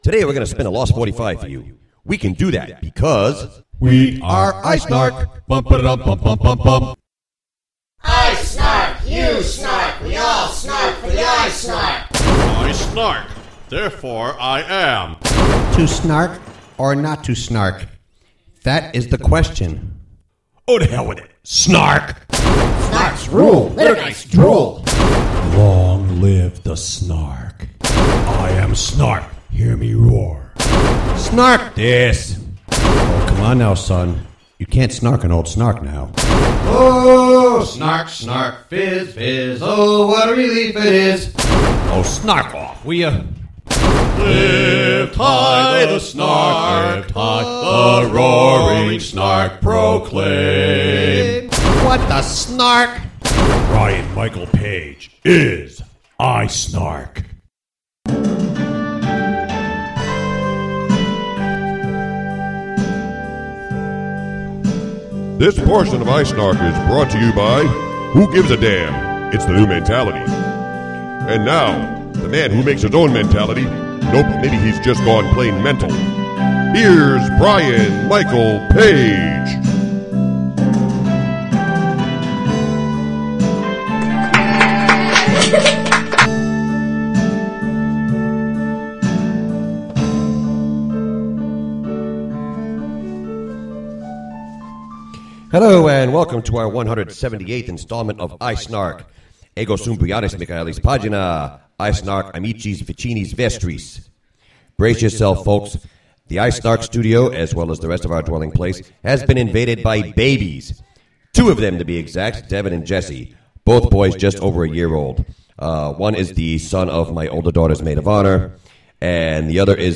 Also, the iSNARK! Studio is invaded by babies, more on the revamping of Psalm 151, and the iSNARK! has re-taken up the sport of bowling!